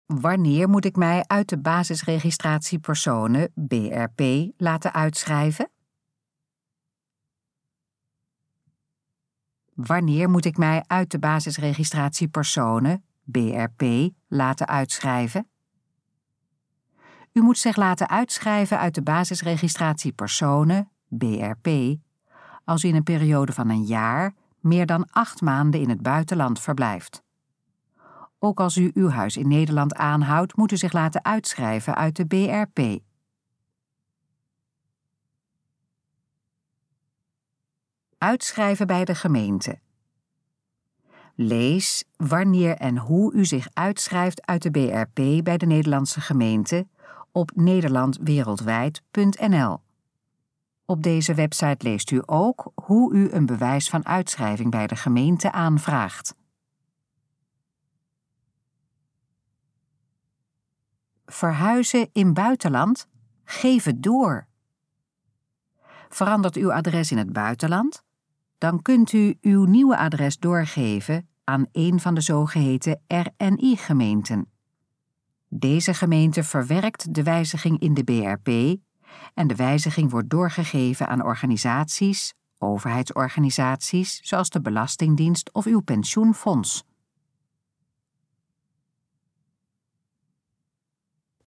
Gesproken versie van: Wanneer moet ik mij uit de Basisregistratie Personen (BRP) laten uitschrijven?
Dit geluidsfragment is de gesproken versie van de pagina: Wanneer moet ik mij uit de Basisregistratie Personen (BRP) laten uitschrijven?